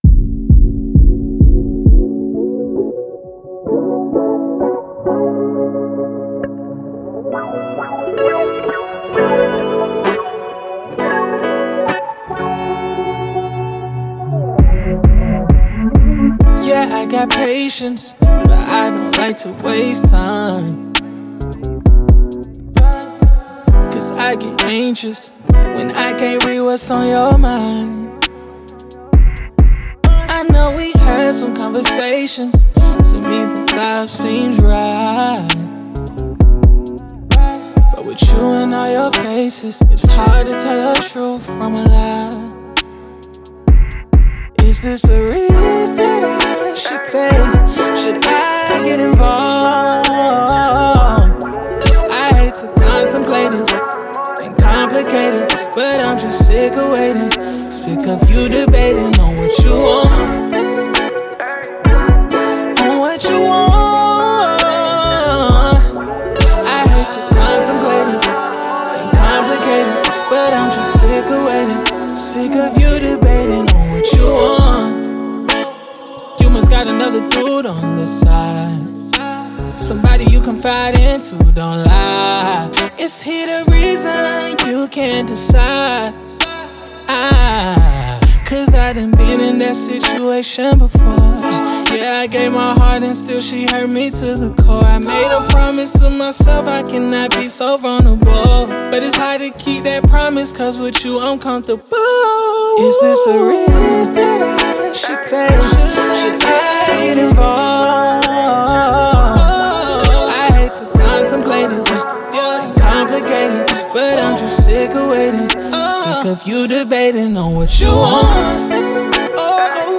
R&B
A Maj